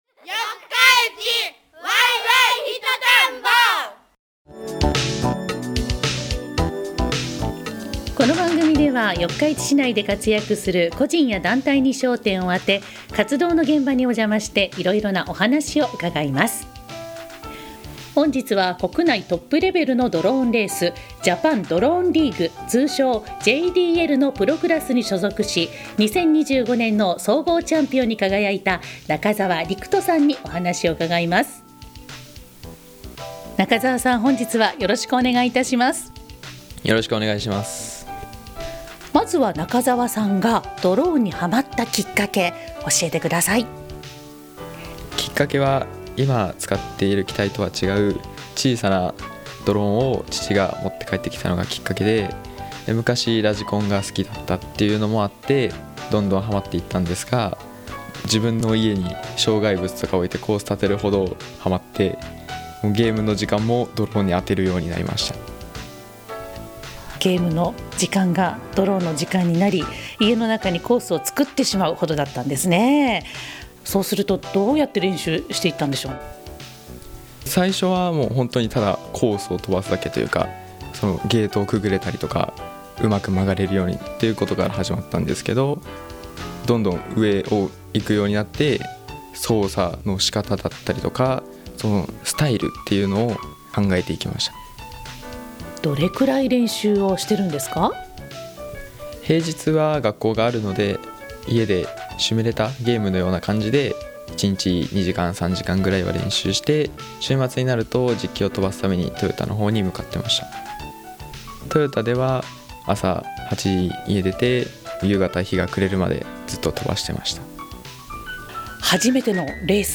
シー・ティー・ワイエフエム（76.8MHz）で放送しています！
第2・第4日曜日 8時54分～、14時54分～（1日に2回、5分間） 四日市で生き生きと活動している人の活動現場の声をお届けします これまで放送した内容をお聴きいただけます。